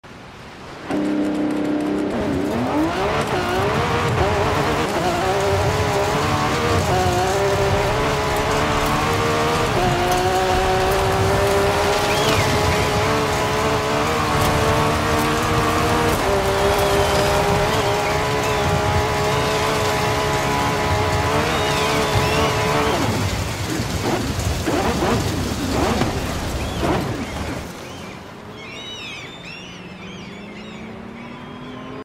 2024 Lamborghini Revuelto Off-Road Launch